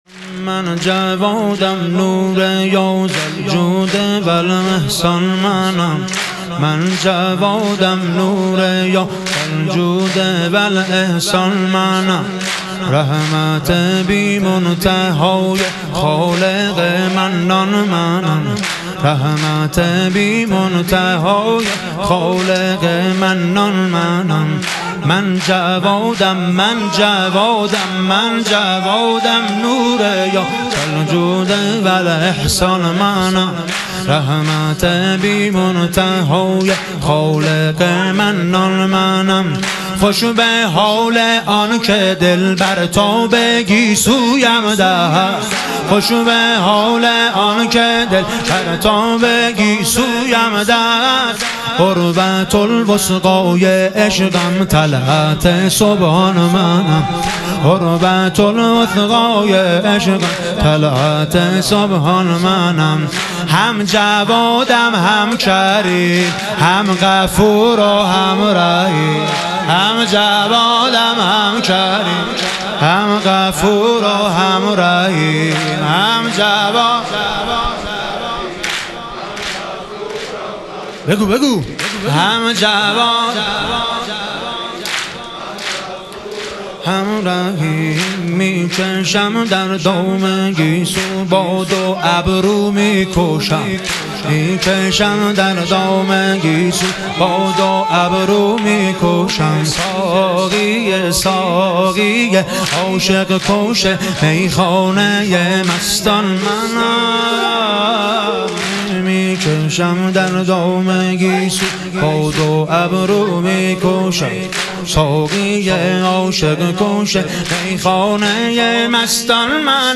شهادت امام جواد علیه السلام - واحد